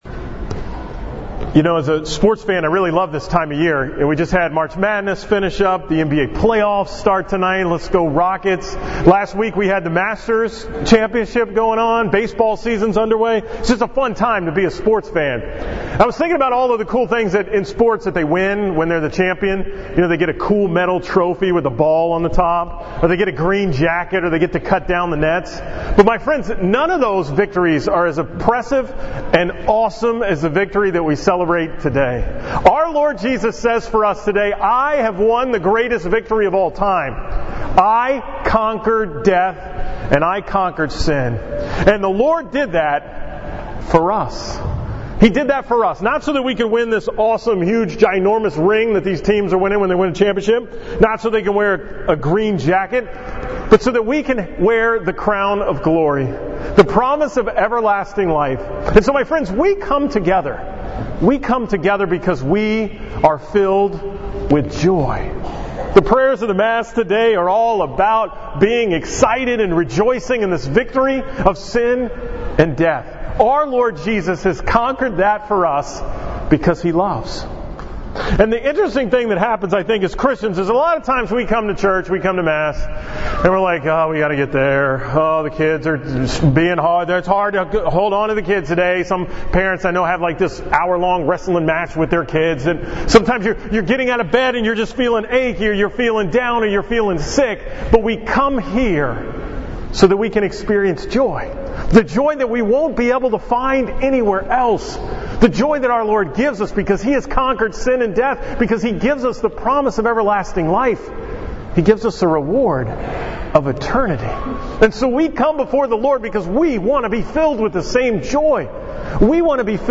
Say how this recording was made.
From the 9 am Mass at St. James in Spring on April 16, 2017